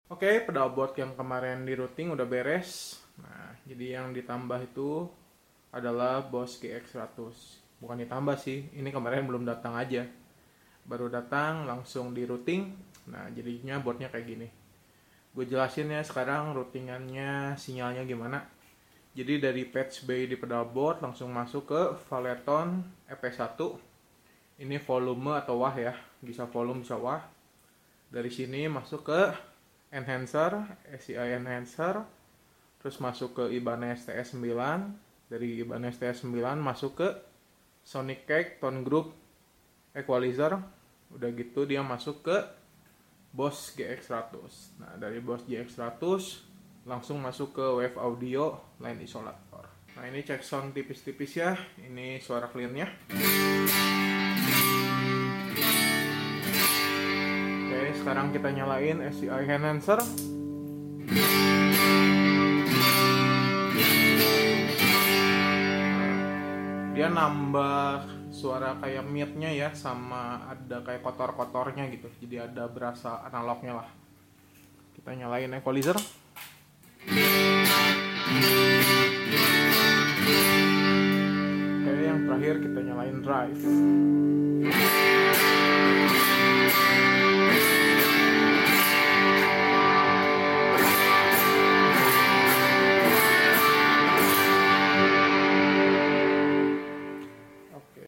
🔥 Patchbay (Input) -> Valeton EP-1 -> Sci Enhancer -> Ibanez TS-9 -> Sonicake Tone Group -> Boss GX-100 -> Wave Audio LI-22 -> Patchbay (Output L R).